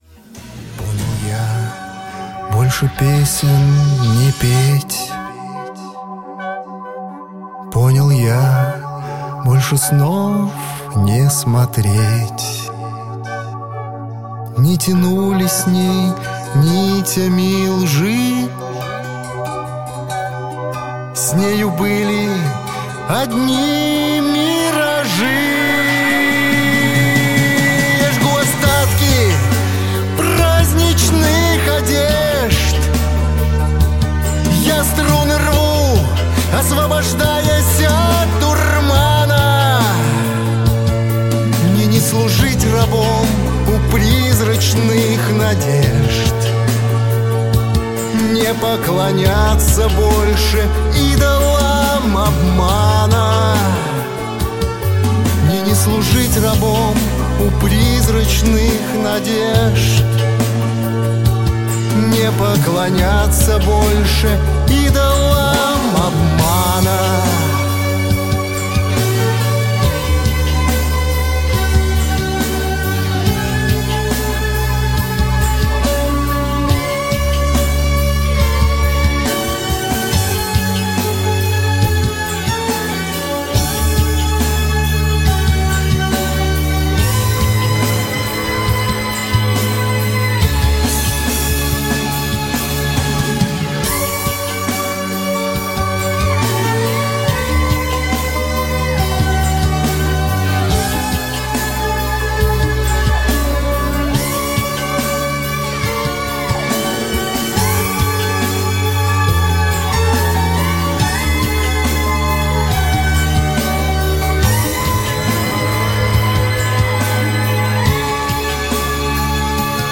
ID Было так... (жив., фрагмент концерта).mp3 Было так...